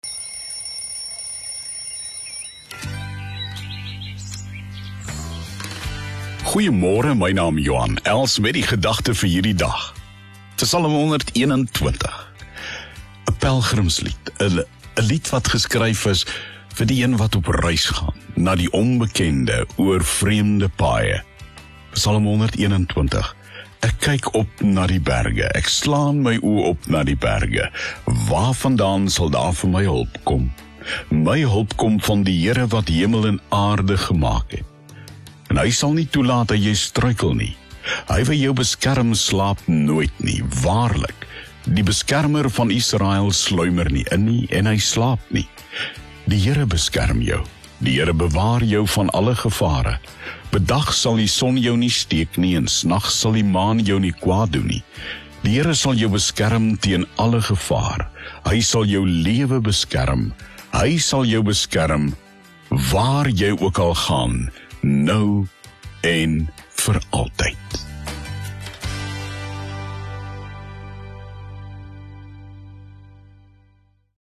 Thought for the Day as heard on OFM on 20 December 2021